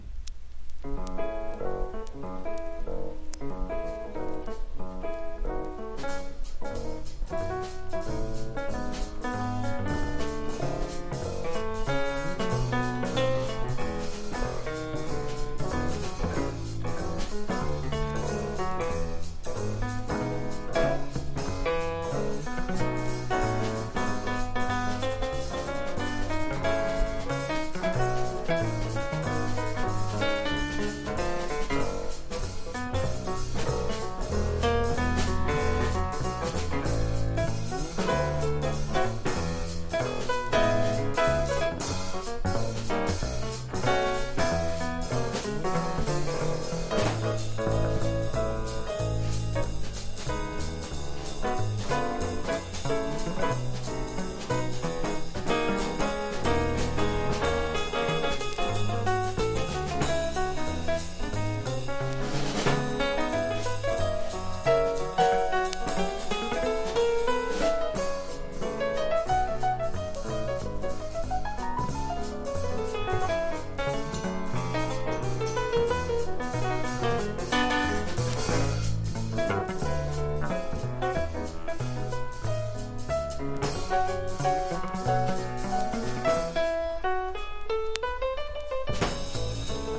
全体的に軽快なスタンダードナンバーが並ぶ聴き易い作品。